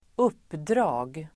Uttal: [²'up:dra:g]